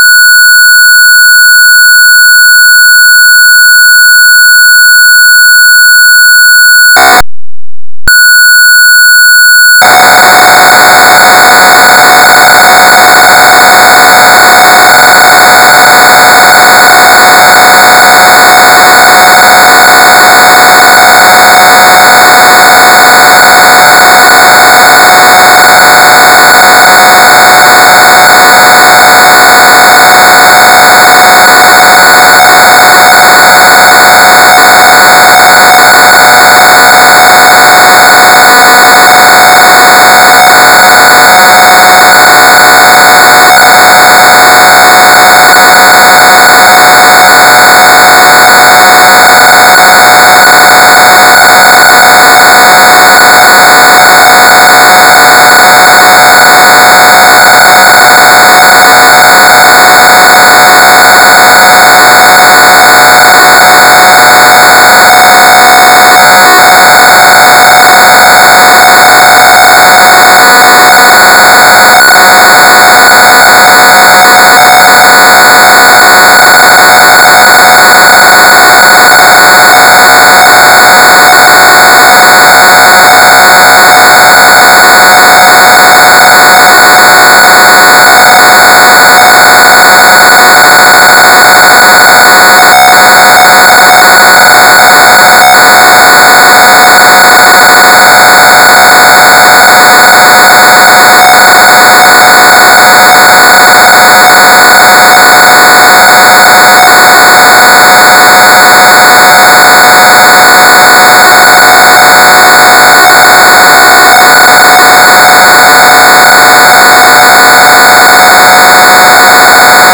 ПК-01 Львов WEB Tape Loader